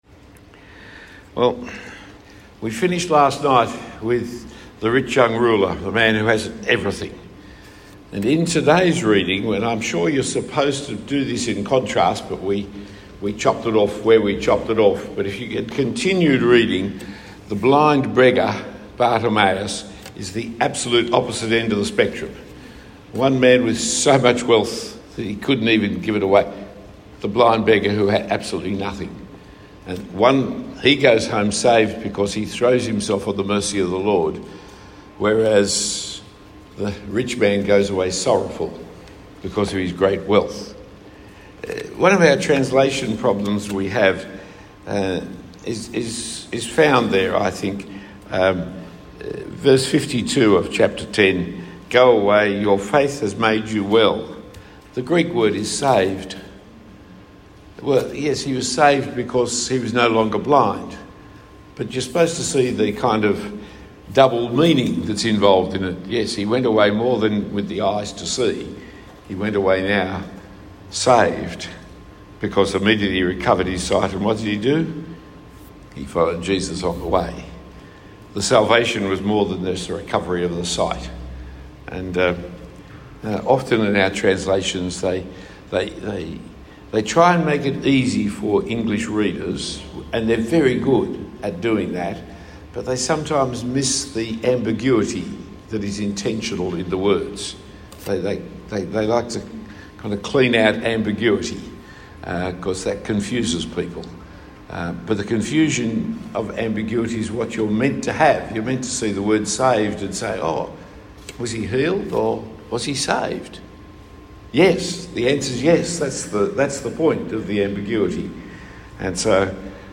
Talk 4 of 4 given at the South Coast MTS Mission Minded Conference.